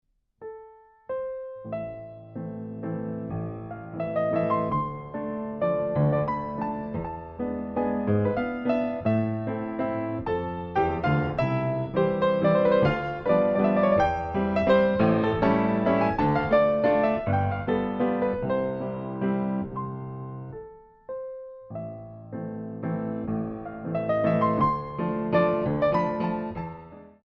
Valzer venezuelani del XIX sec.
Pianoforte
Registrazione audiophile realizzata nei mesi di agosto e settembre 2005 con microfoni e pre-amplificatore a valvole, campionamento a 96 kHz.